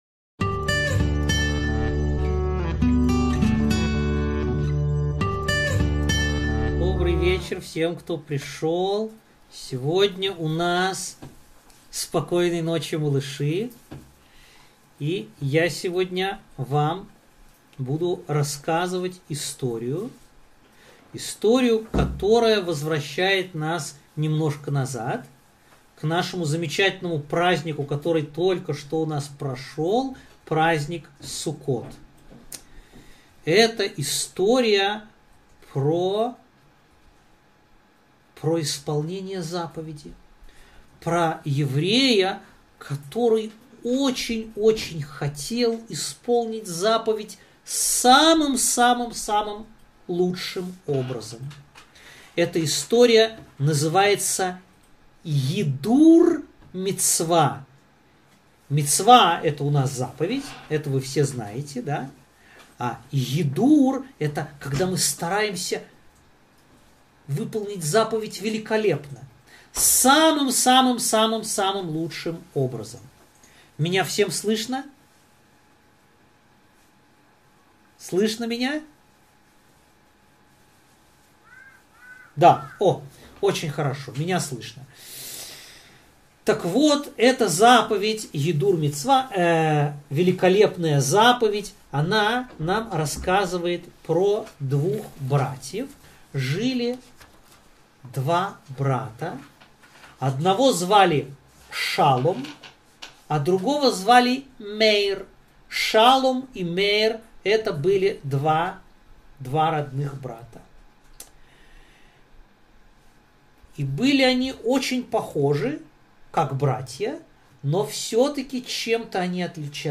поучительная история
В конце записи дети